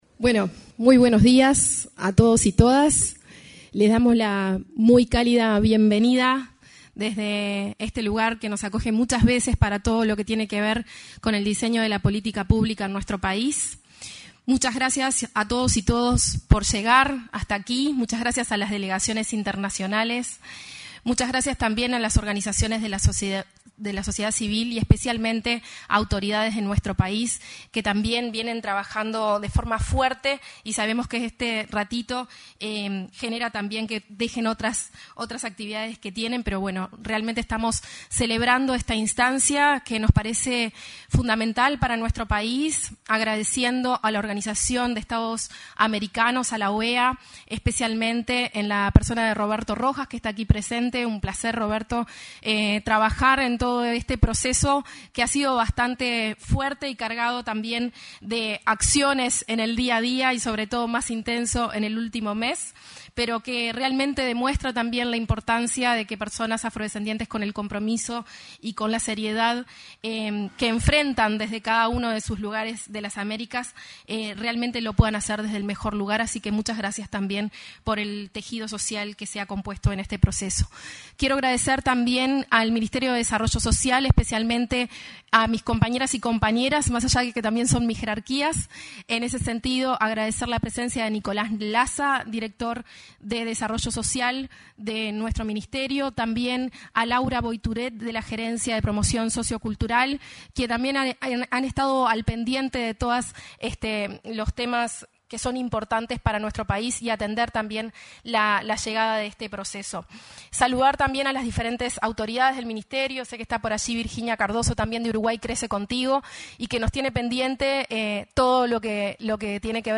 El ministro de Desarrollo Social, Gonzalo Civila, y la directora de Promoción de Políticas Públicas para Afrodescendientes, Leticia Rodríguez,
participaron de la apertura de la IV Reunión Plenaria de Riafro.